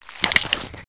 auto_raise.wav